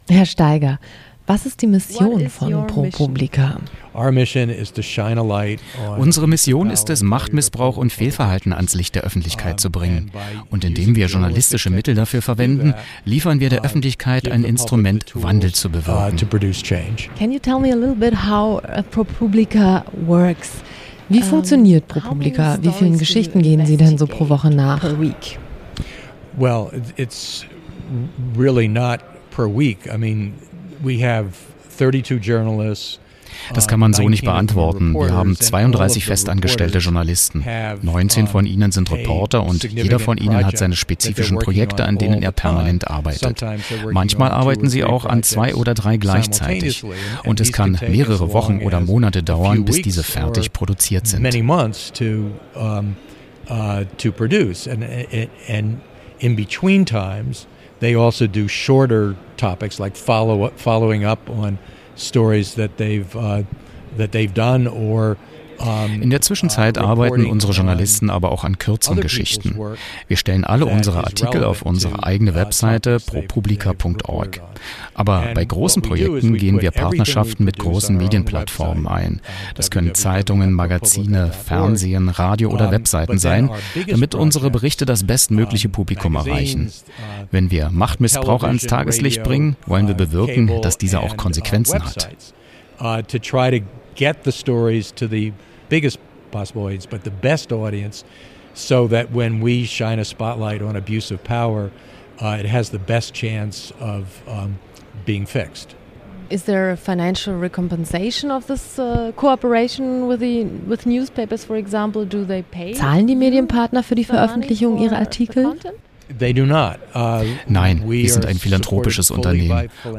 * Paul Steiger, Ex-Chefredakteur des Wall Street Journal (1991-2007), Gründer von ProPublica, einem Online-Portal für investigativen Journalismus finanziert über Spenden.
Wo: Potsdam
Wann: rec.: 08.09.2010; veröffentlicht im radioeins-Medienmagazin (rbb) vom 15.01.2011